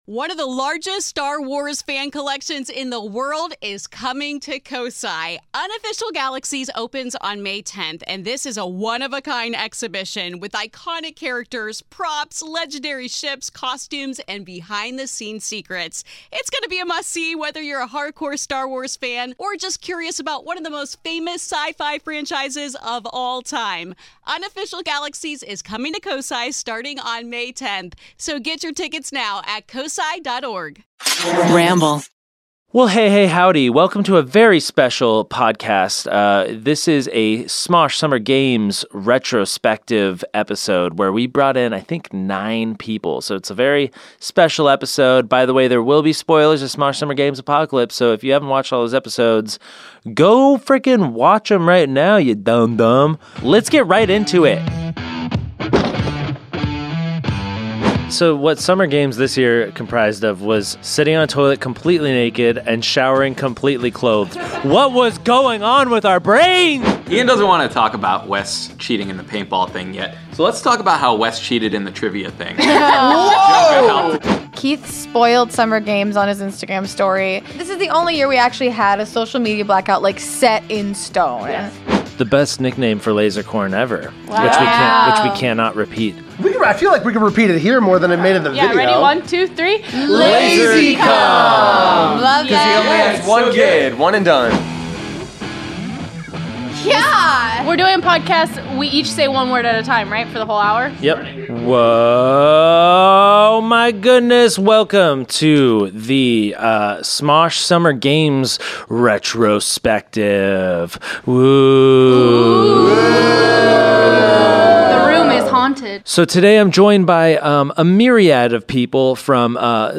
Nine of us gathered into a conference room